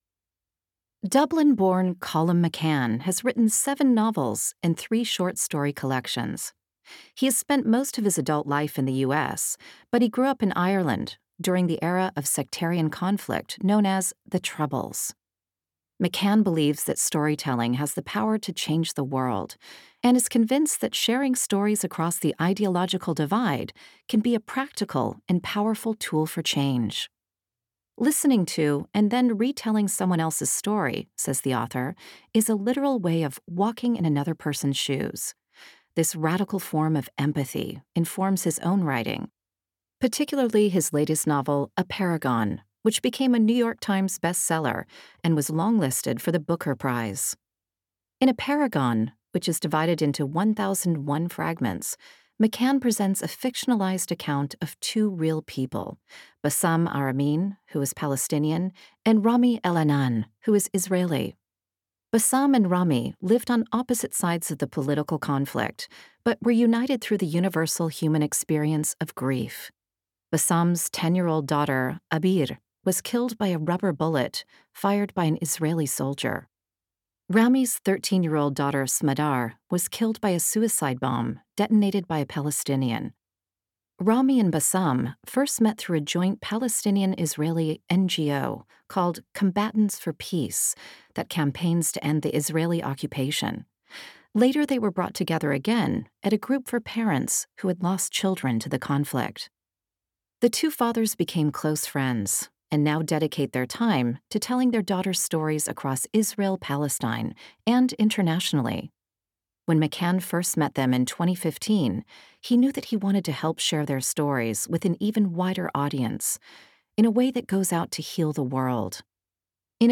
Speaker (American accent)